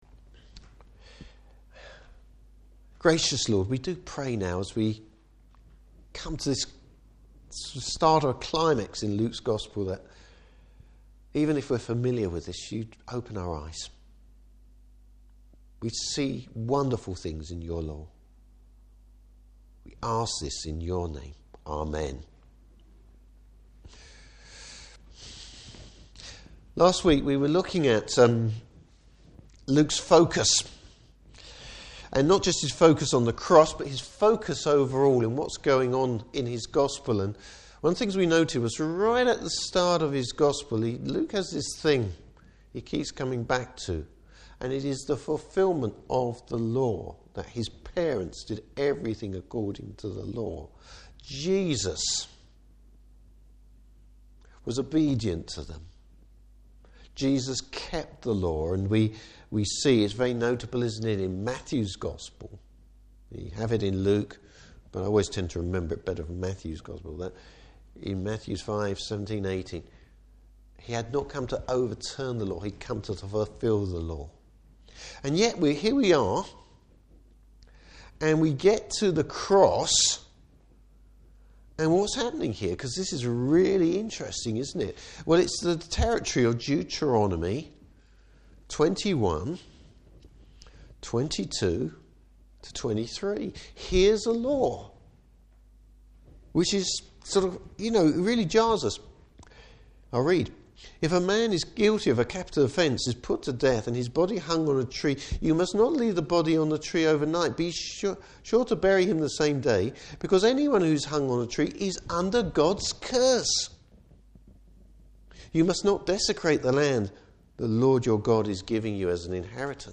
Service Type: Morning Service Different reactions to Jesus’s death, but Luke’s gives us the full picture!